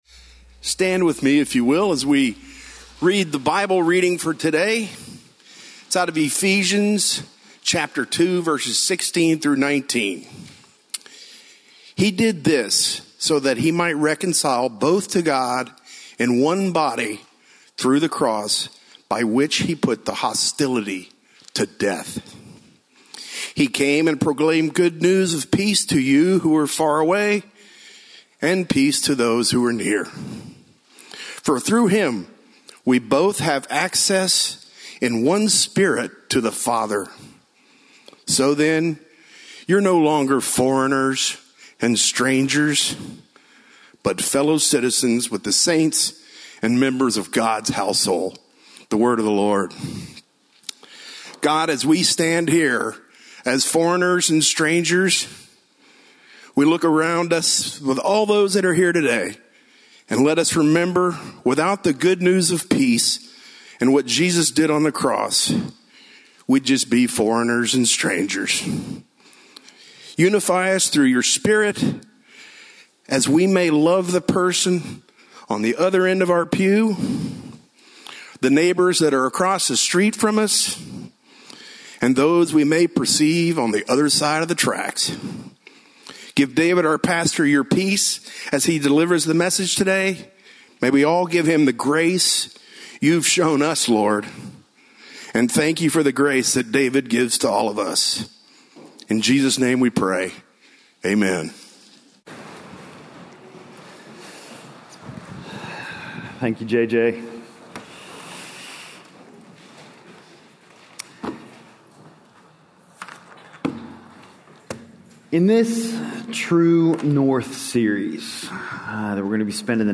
Finding Our Way with Racial Reconciliation - Sermon - Lockeland Springs